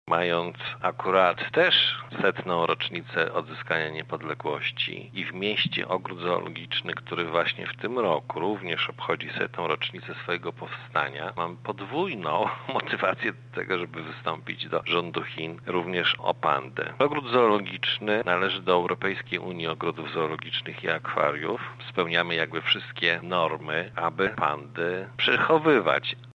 • mówi Andrzej Zastąpiło, Zastępca Prezydenta Miasta Zamość.